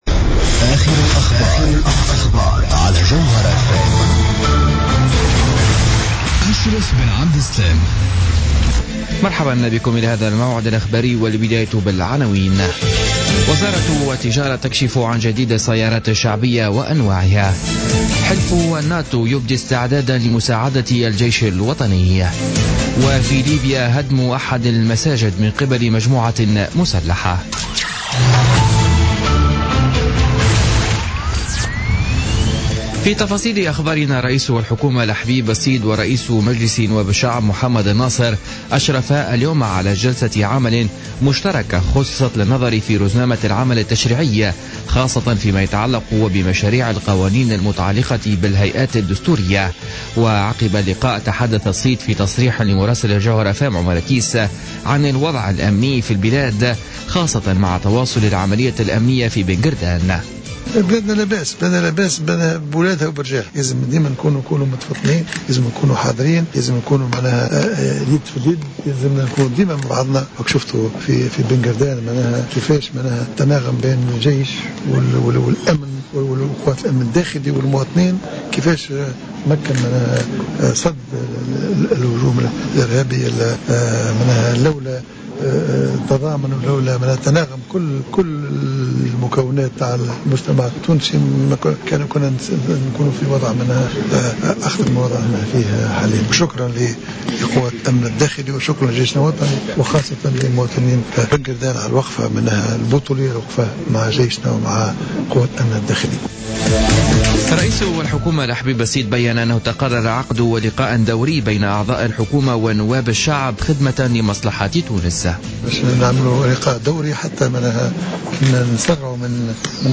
نشرة أخبار السابعة مساء ليوم الأربعاء 16 مارس 2016